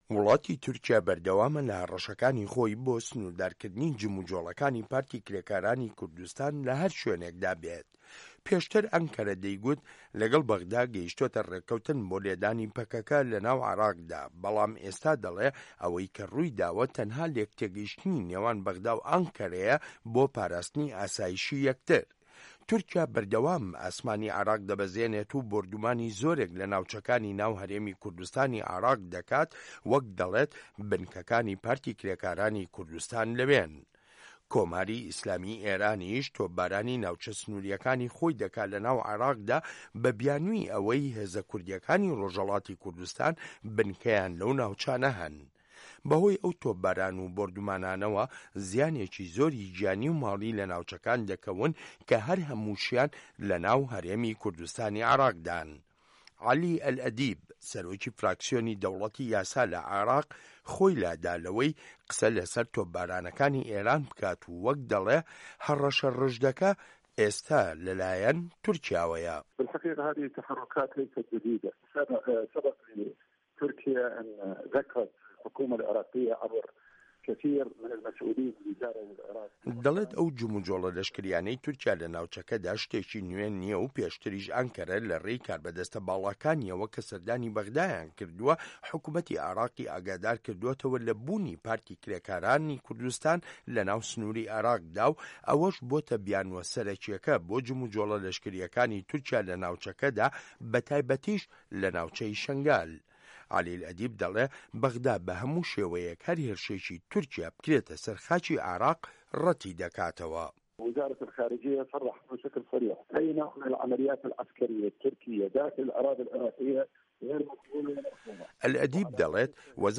ڕاپۆرت لەسەر بنچینەی لێدوانەکانی عەلی ئەلئەدیب